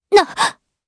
Chrisha-Vox_Damage_jp_02.wav